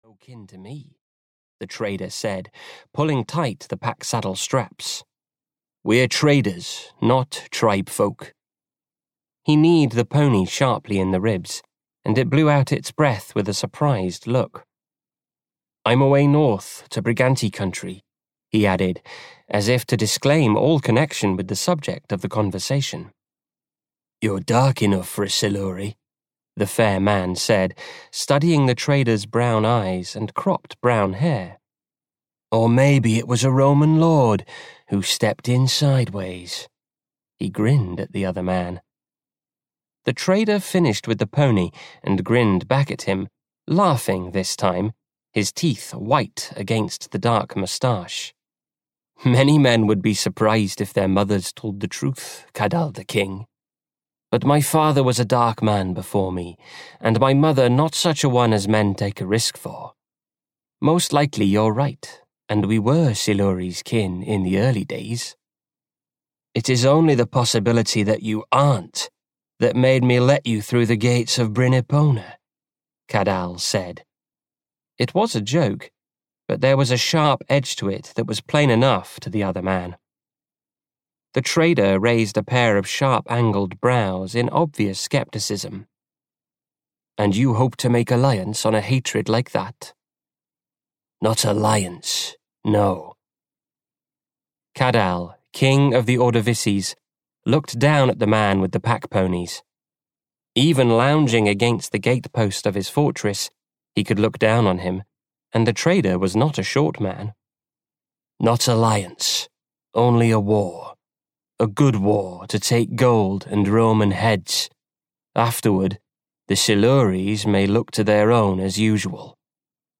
Barbarian Princess (EN) audiokniha
Ukázka z knihy